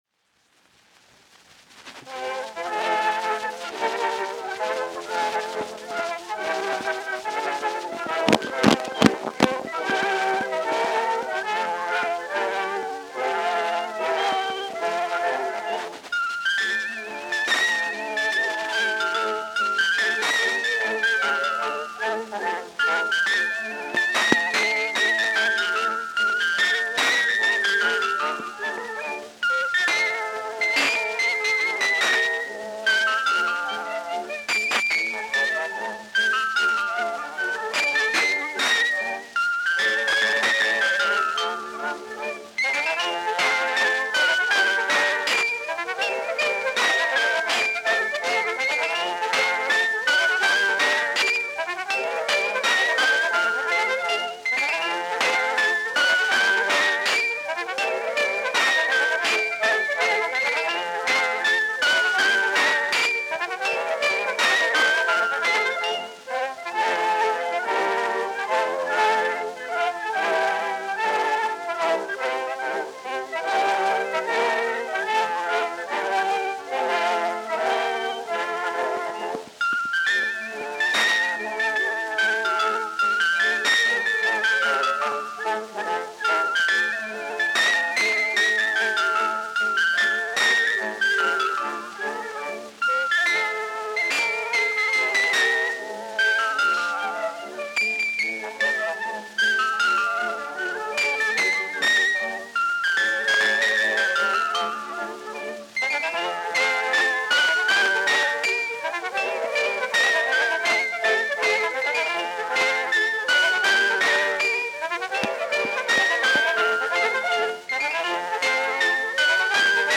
Orchestra bells.